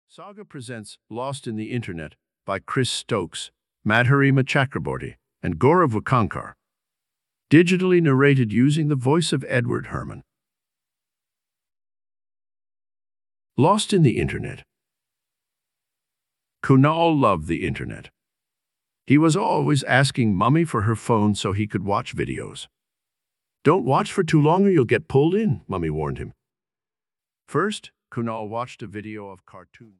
Audiobook Lost in the Internet written by Gaurav Wakankar, Madhurima Chakraborty and Kris Stokes. Kunal gets lost in the Internet and doesn’t know how to get out.
Join him on a sci-fi journey through the Internet as he finds his way home and learn about what the internet is and how data travels!Digitally narrated using the voice of Edward Herrmann."Lost in the Internet" was originally published by Pratham Books on the online platform StoryWeaver.